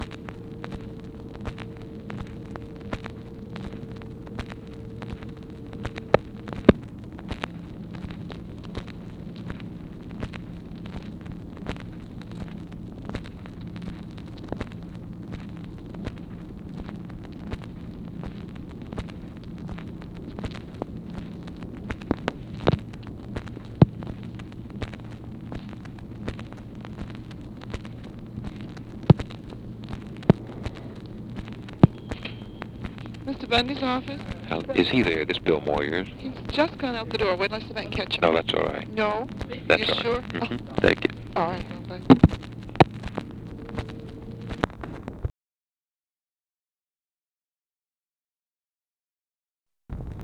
Conversation with BILL MOYERS and MCGEORGE BUNDY'S OFFICE, April 2, 1964
Secret White House Tapes